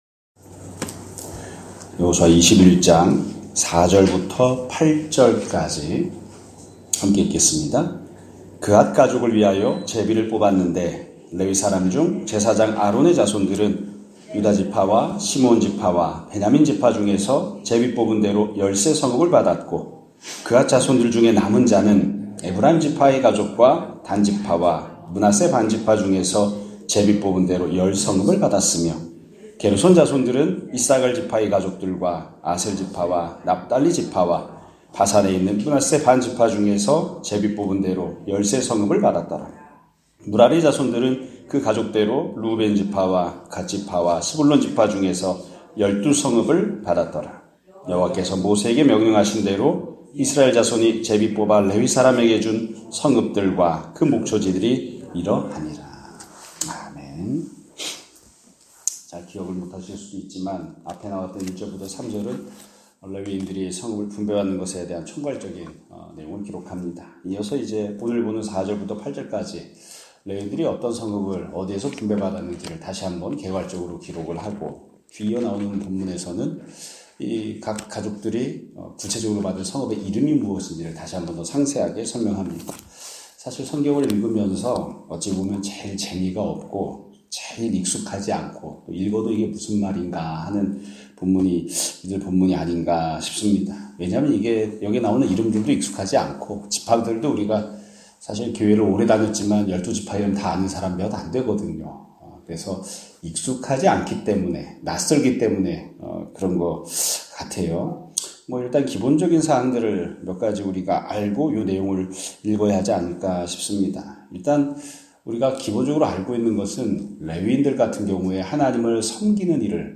2025년 1월 20일(월요일) <아침예배> 설교입니다.